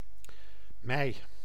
Ääntäminen
IPA: [mɛj]